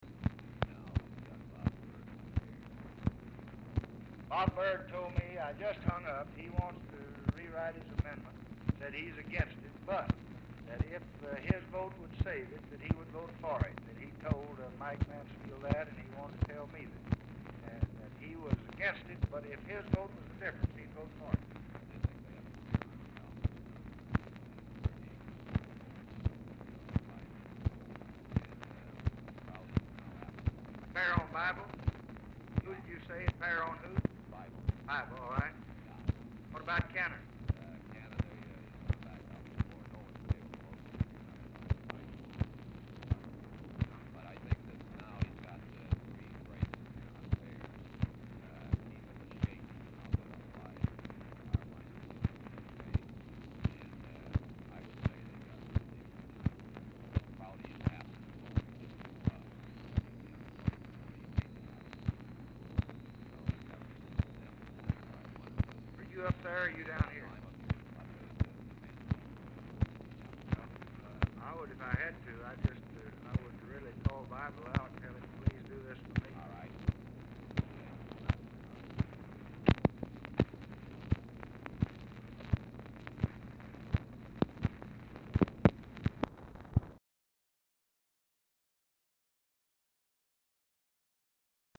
RECORDING STARTS AFTER CONVERSATION HAS BEGUN; POOR SOUND QUALITY; O'BRIEN IS ALMOST INAUDIBLE
Format Dictation belt
Specific Item Type Telephone conversation Subject Congressional Relations Housing Legislation Welfare And War On Poverty